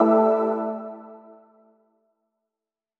Pickup Holy.wav